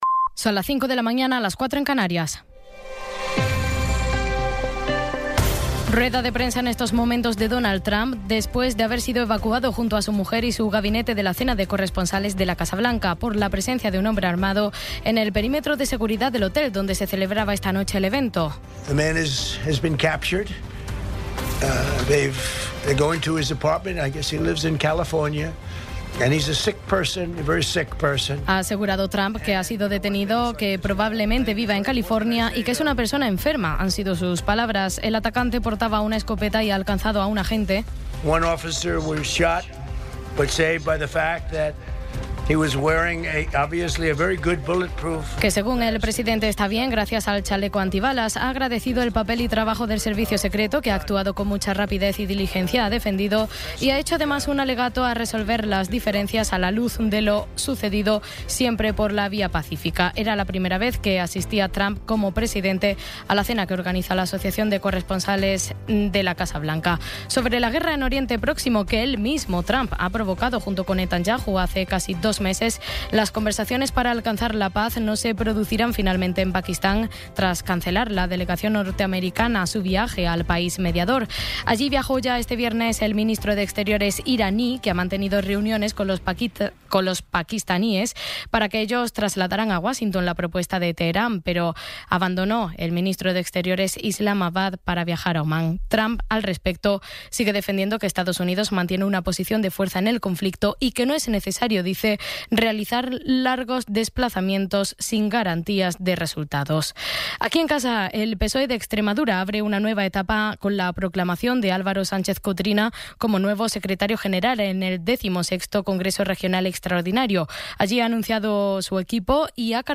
Resumen informativo con las noticias más destacadas del 26 de abril de 2026 a las cinco de la mañana.